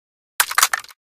reload_loop.ogg